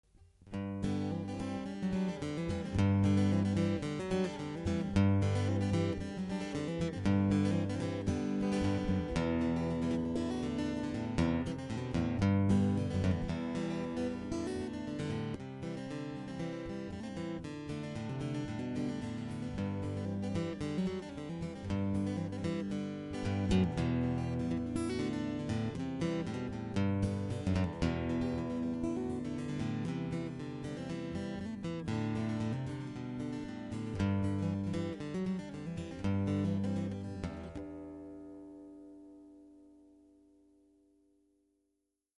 They are all different and each one has a special sound.
Here's a sample of the sound of this guitar (by a great US player ;) ) :
And that was the first time I've heard a stereo Ovation.
Test_AdII_Stereo.mp3